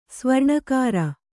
♪ svarṇakāra